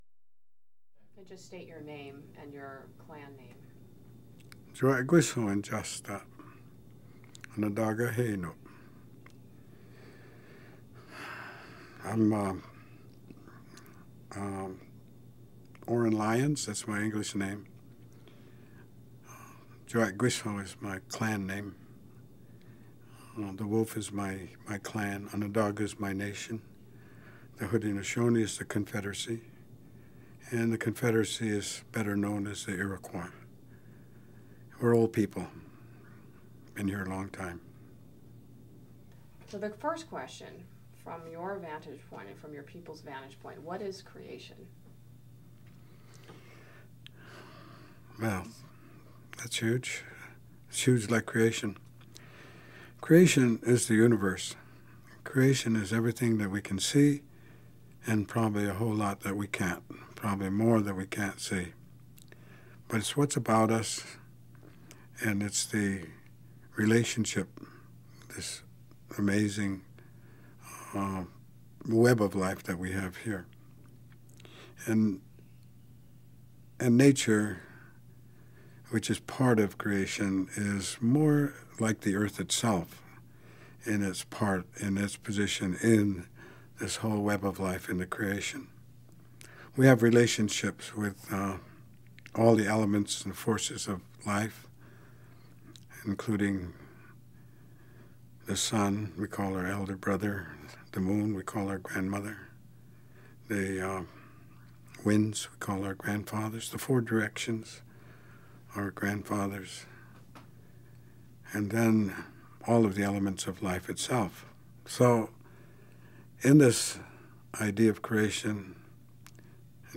This film is one in series of 72 interviews, comprising The 11th Hour Research Tapes, with remarkable people of deep knowledge and wisdom in their fields.